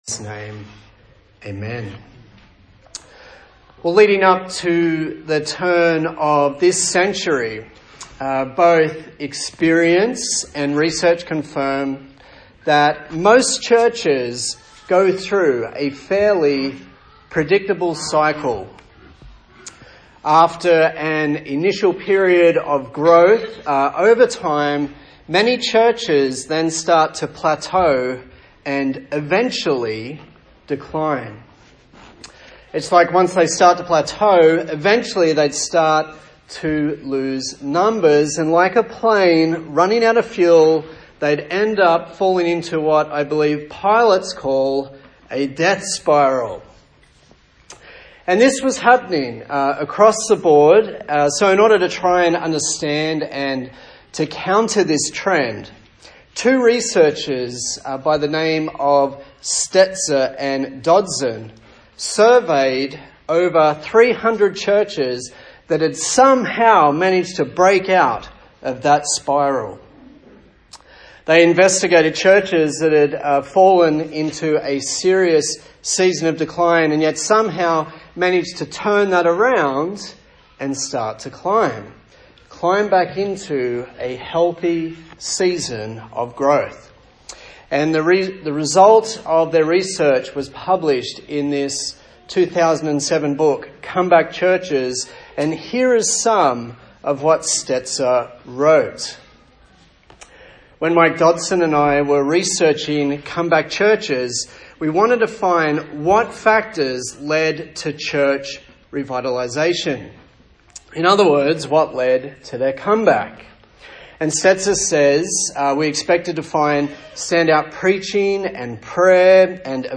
Service Type: Sunday Morning A sermon in the series on the book of 1 Peter